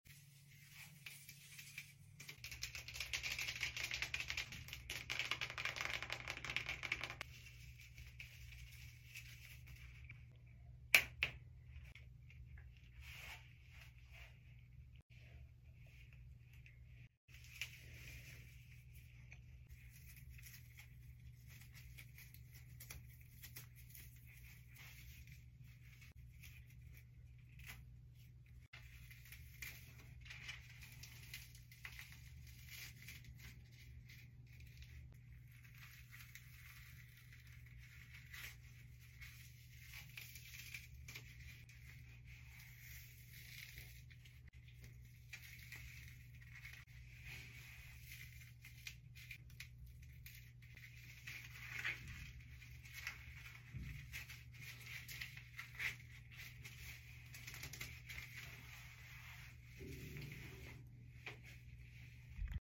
ASMR pulling apart my hair sound effects free download